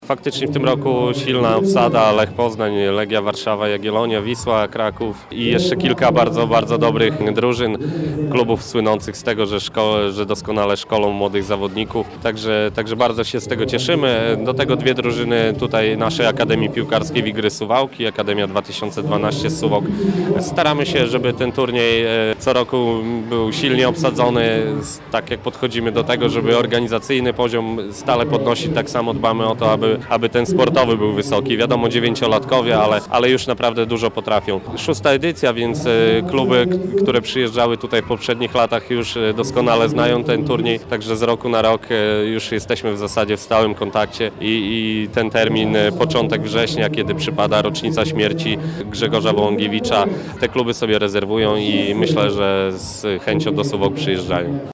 w rozmowie z Radiem 5